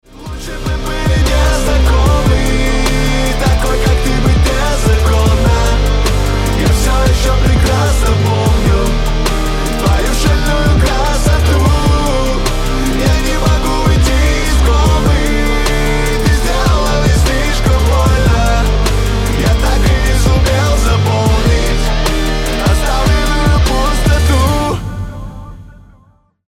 гитара
мужской голос
грустные